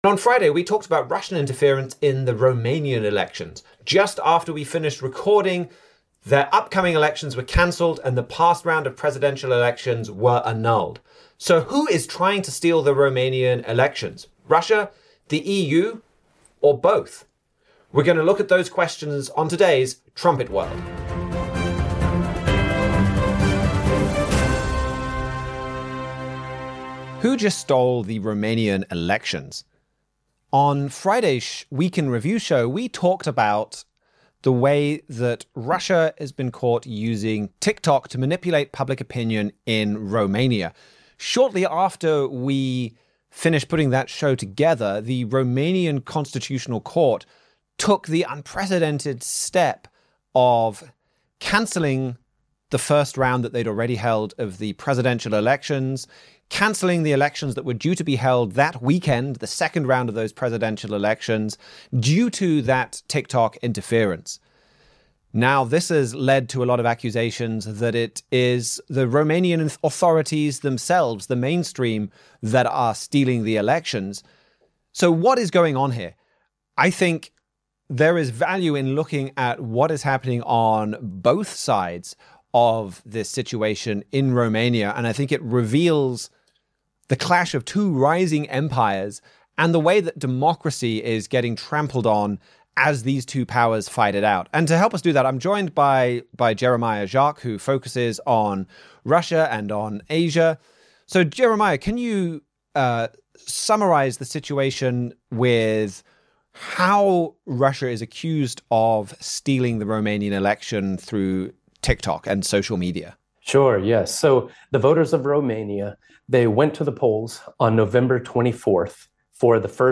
Join the discussion as Trumpet staff members compare recent news with Bible prophecy.